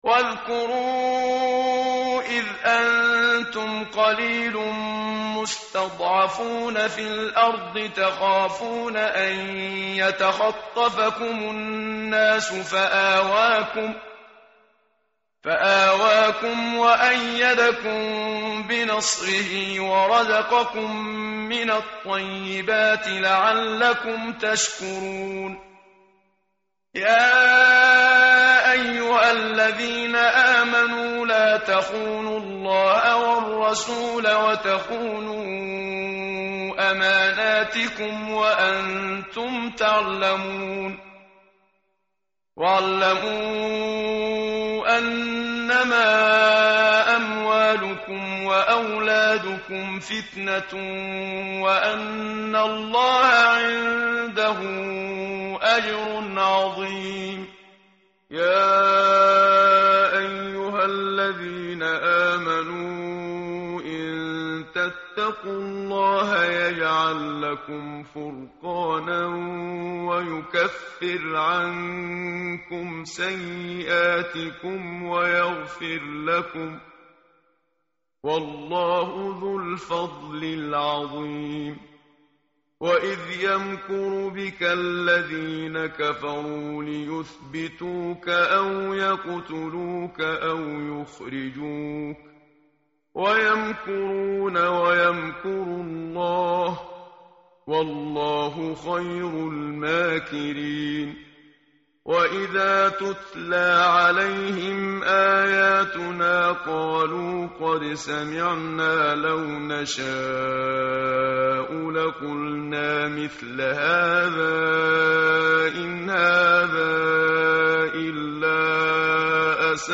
tartil_menshavi_page_180.mp3